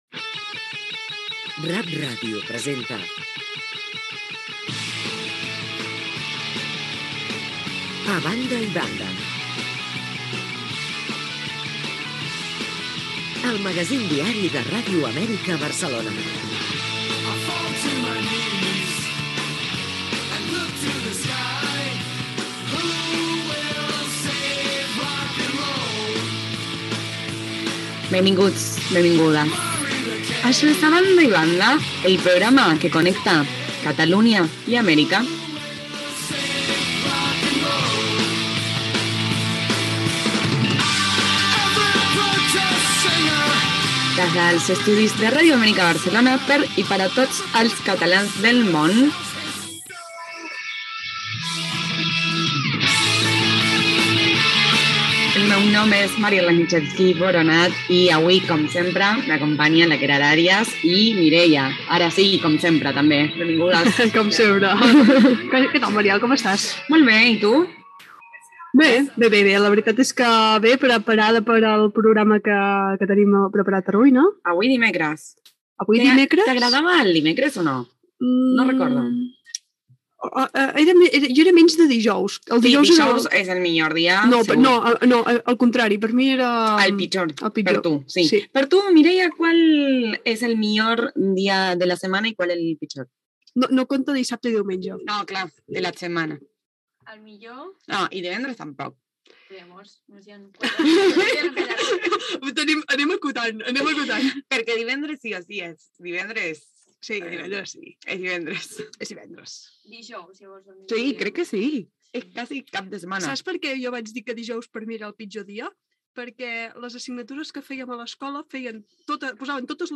Identificació de l'emissora, salutació inicial del programa
Gènere radiofònic Info-entreteniment